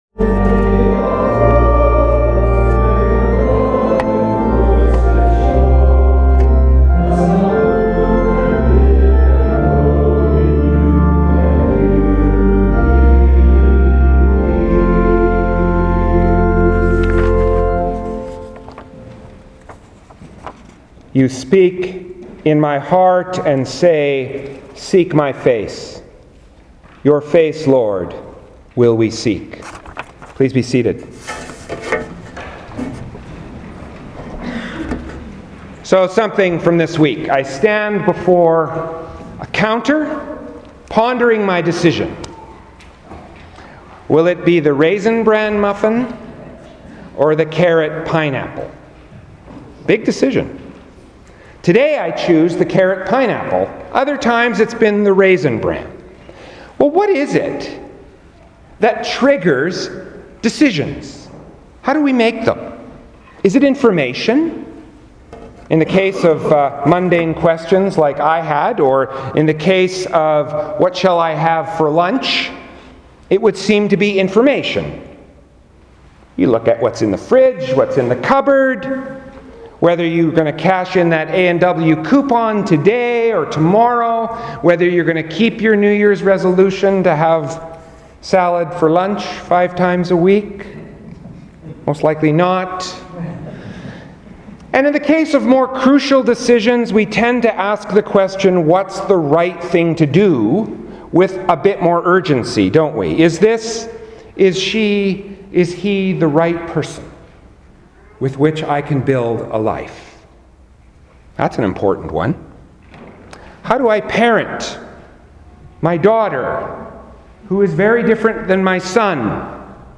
Sermons | Parish of St. Matthew Anglican Church